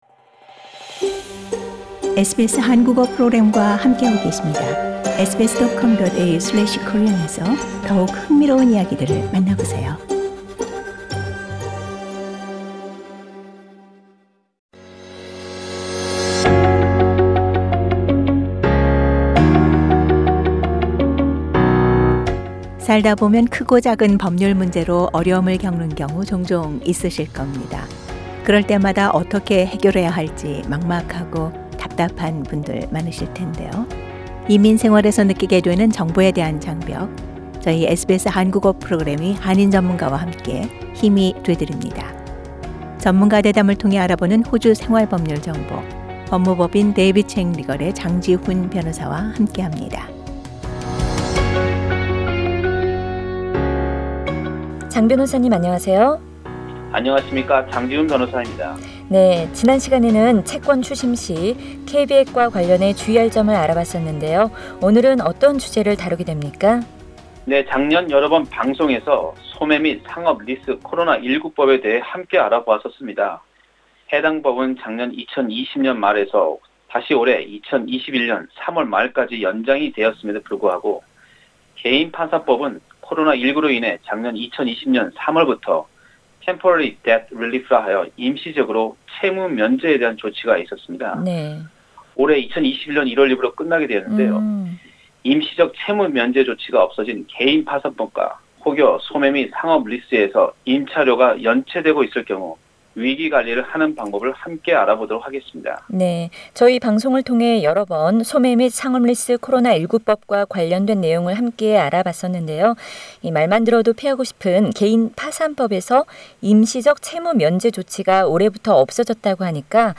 전문가 대담을 통해 알아보는 호주 생활법률정보